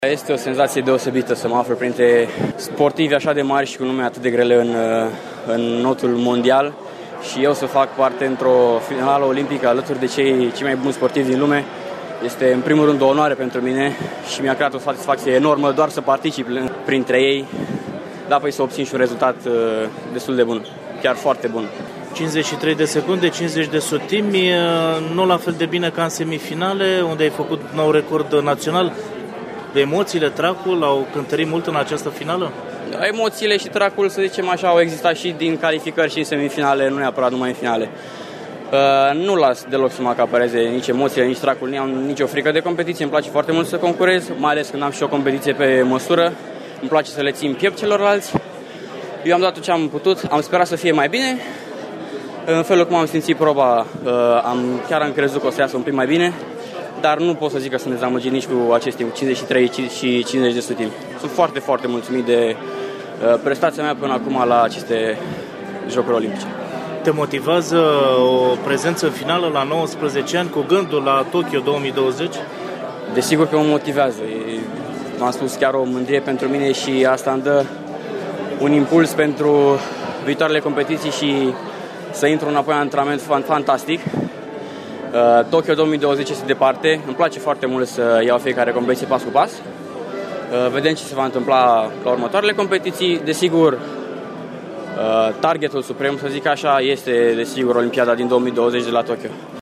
Un interviu audio cu Robert Glință, după finală